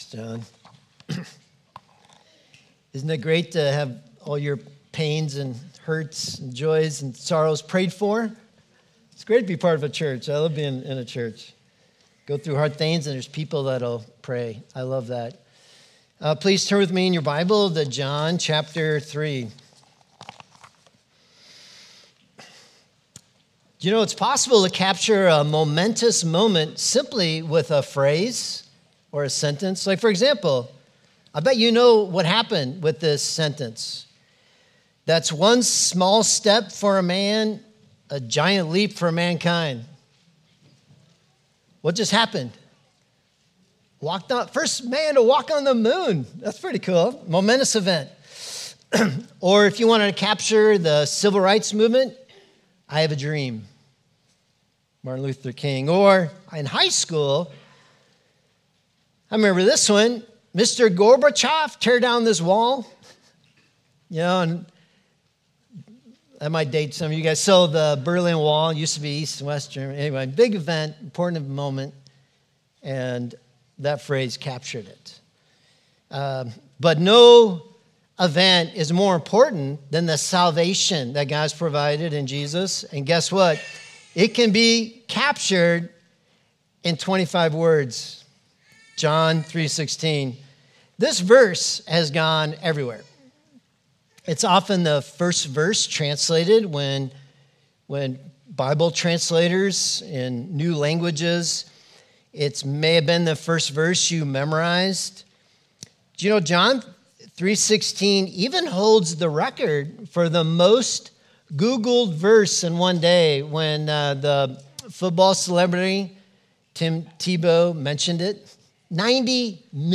Sermon from Luke 1:57-80 in St. Charles, IL